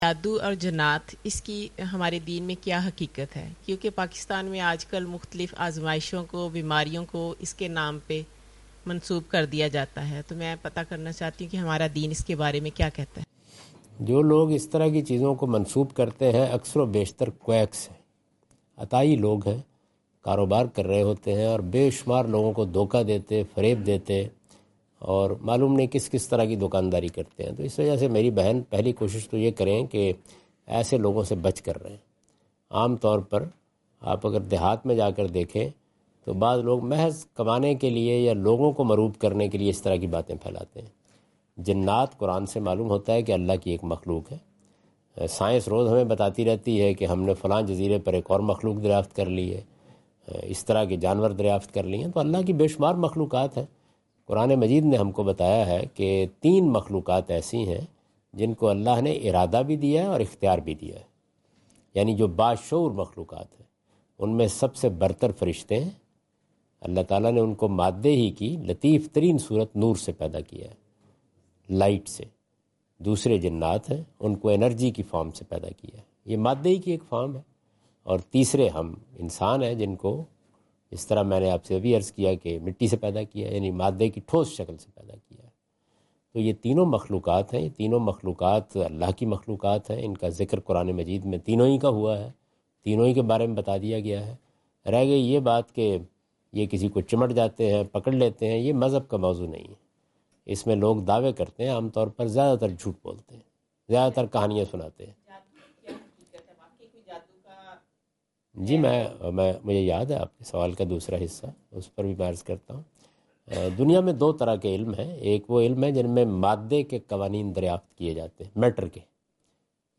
Javed Ahmad Ghamidi answer the question about "reality of magic and jinn’s in islam" during his Australia visit on 11th October 2015.
جاوید احمد غامدی اپنے دورہ آسٹریلیا کے دوران ایڈیلیڈ میں "جادو اور جنات کی اسلام میں حیثیت " سے متعلق ایک سوال کا جواب دے رہے ہیں۔